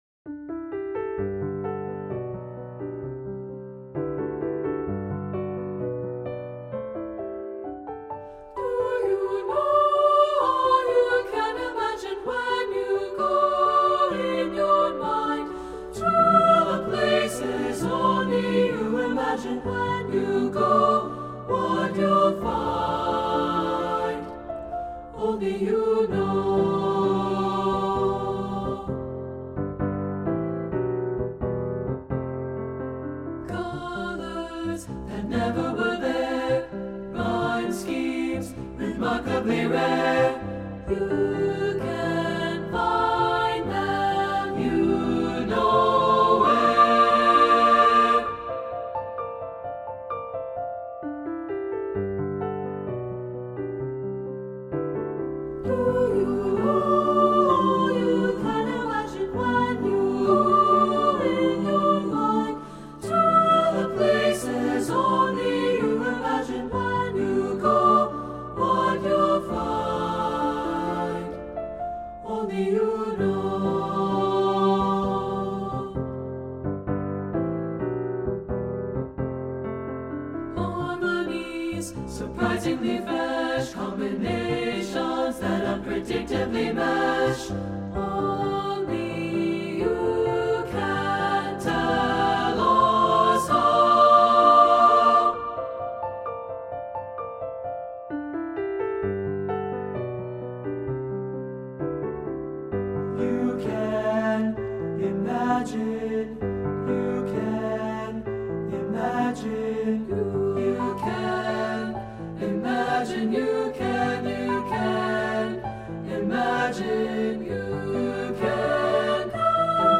• Piano
Studio Recording
Ensemble: Three-part Mixed Chorus
Key: G major
Tempo: h = 70
Accompanied: Accompanied Chorus